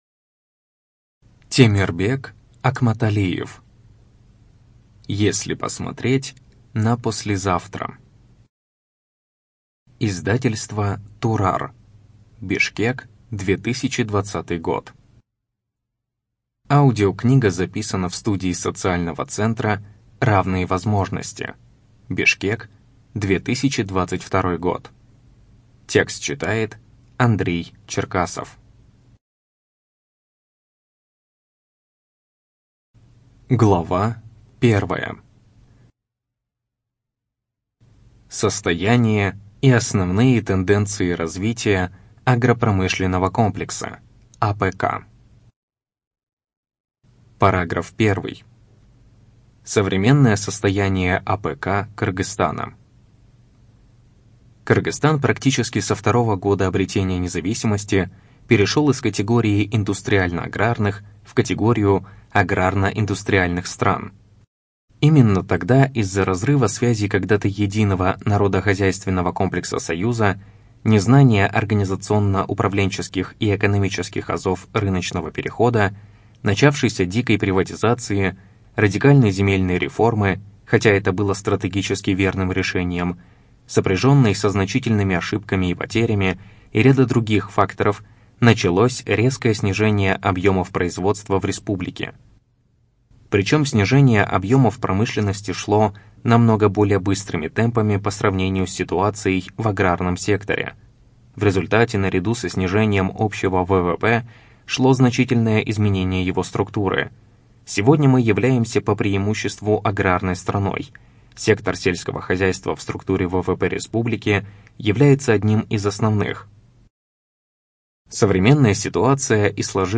Студия звукозаписиСоциальный центр "Равные возможности" (Бишкек)